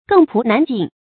更仆難盡 注音： ㄍㄥ ㄆㄨˊ ㄣㄢˊ ㄐㄧㄣˋ 讀音讀法： 意思解釋： 同「更仆難數」。